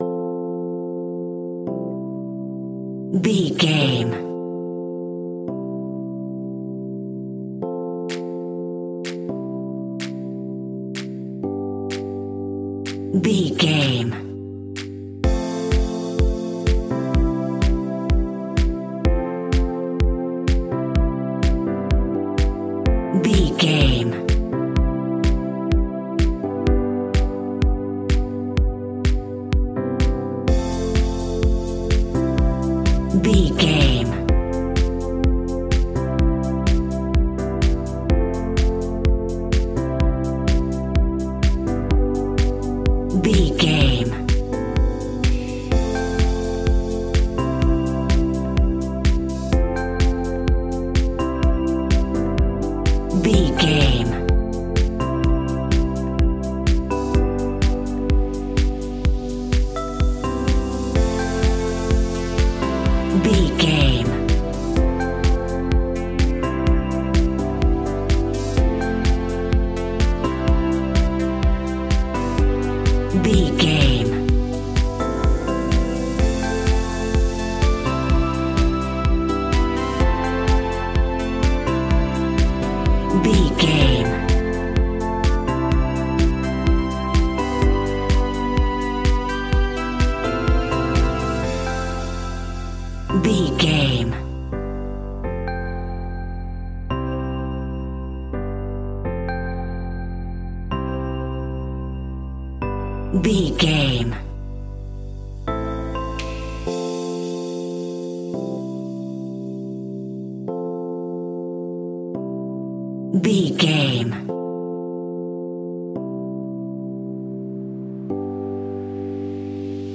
Joyful Tropical House Full Mix.
Ionian/Major
groovy
uplifting
energetic
drums
synthesiser
drum machine
electric piano
house
electro house
synth leads
synth bass